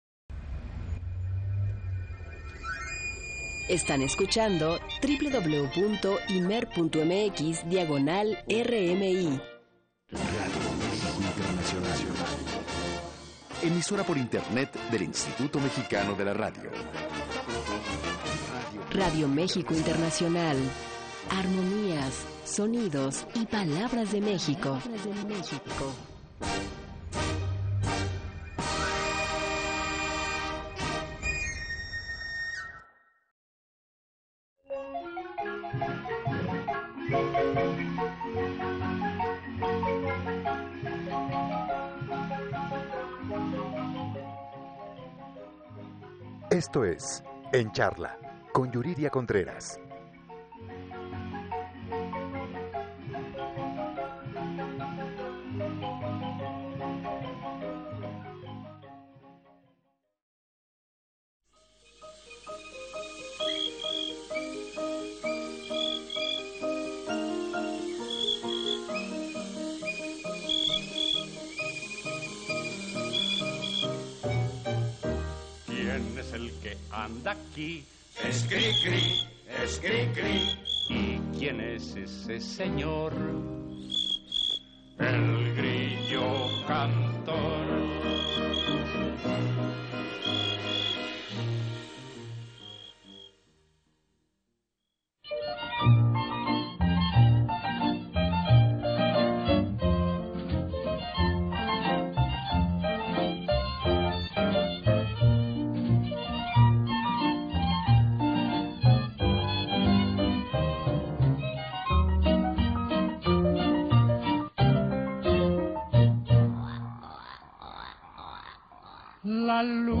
entrevista_cri-cri.mp3